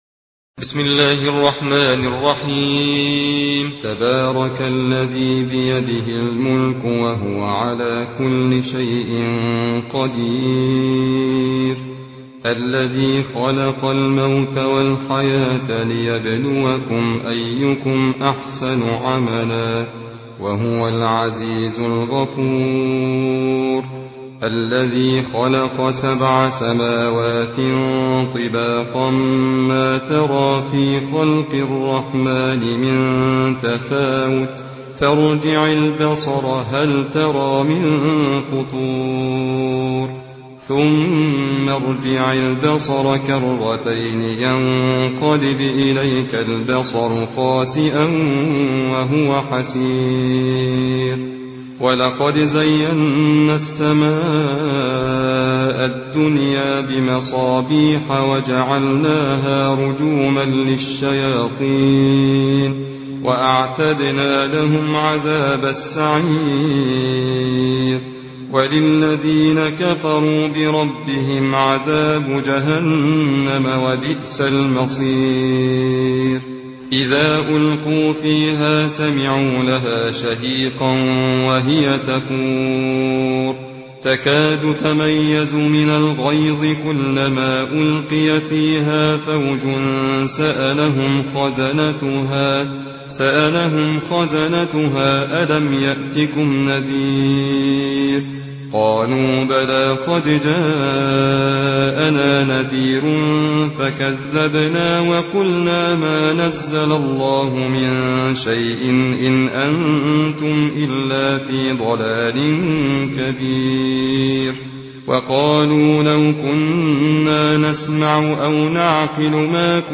برواية حفص عن عاصم